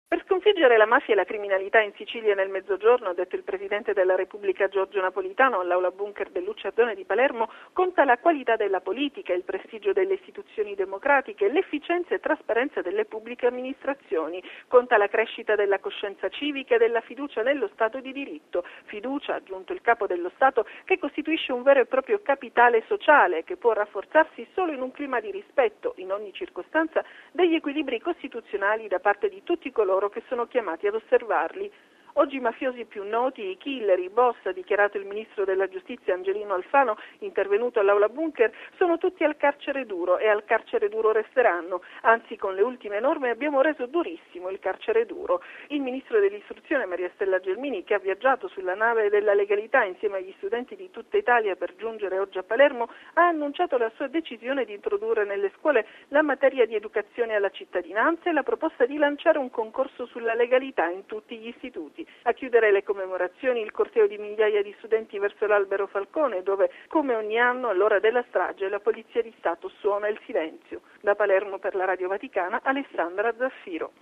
Oggi un lungo applauso ha seguito il minuto di silenzio suonato dalla Polizia di Stato davanti all’albero che porta il nome di Giovanni Falcone.